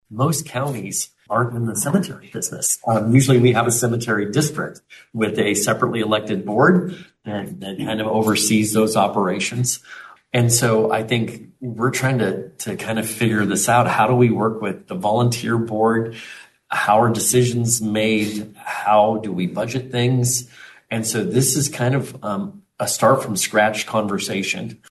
County Administrator Todd Mielke says the grant could help address those deferred needs.